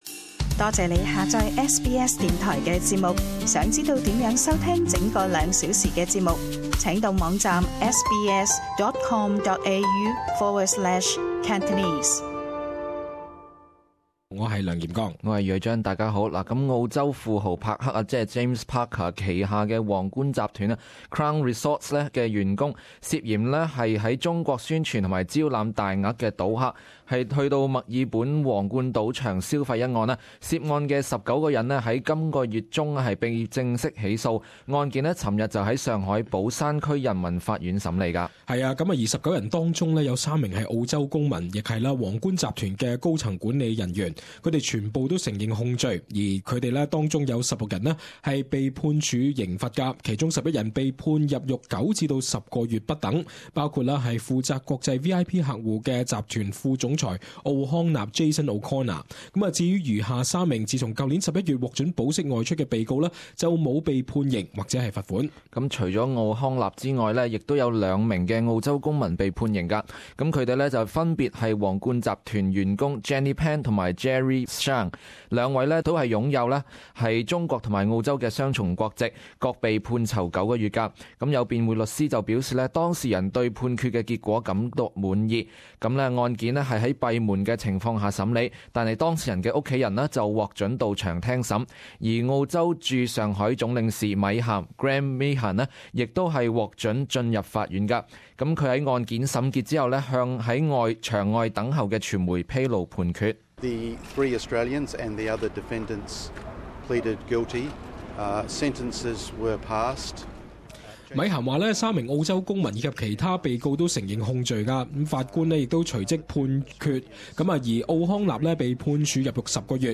【時事報導】皇冠事件：被告判囚 罰款170萬元